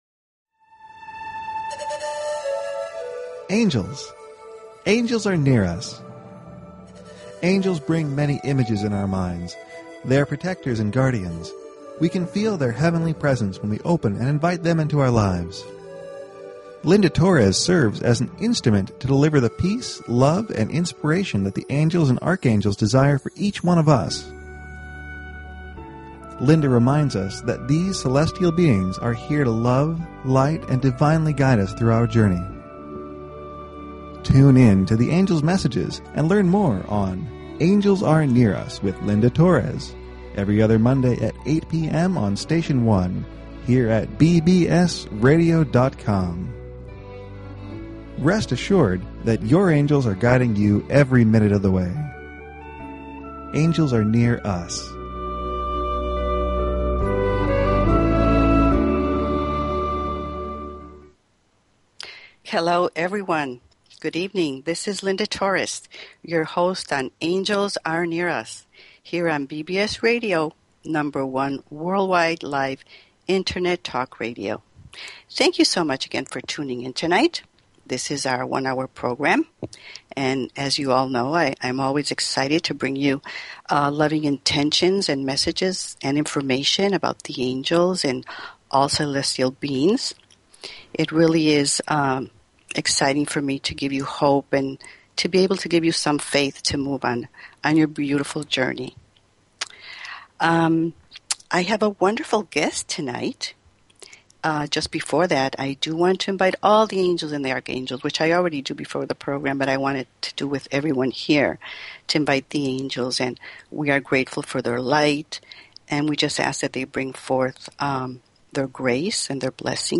Talk Show Episode, Audio Podcast, Angels_Are_Near_Us and Courtesy of BBS Radio on , show guests , about , categorized as
The last 30 minutes of the show the phone lines will be open for questions and Angel readings.